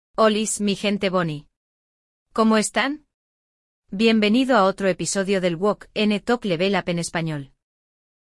Hoy vamos acompanhar uma conversa entre os amigos Fernando e Manuel. Os dois estão discutindo, mas por que será?